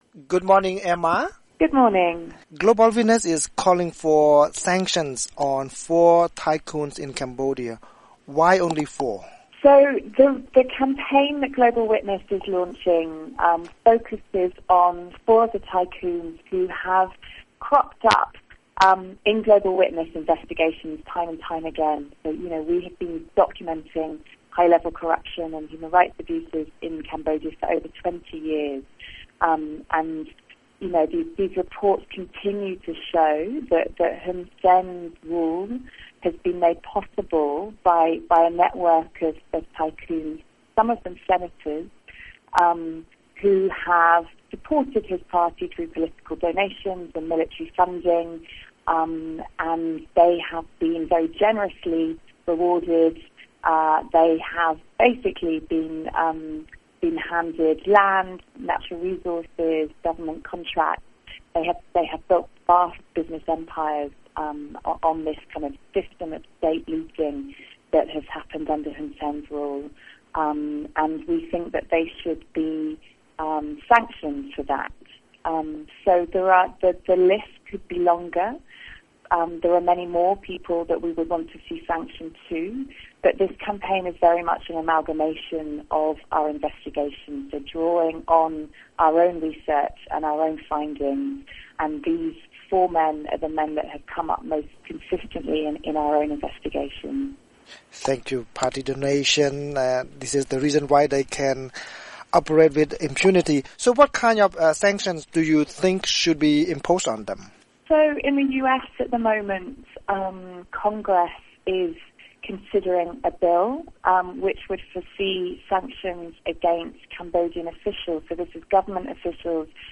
VOA Khmer Interview: Global Witness Urges US Sanctions on Four Cambodian Tycoons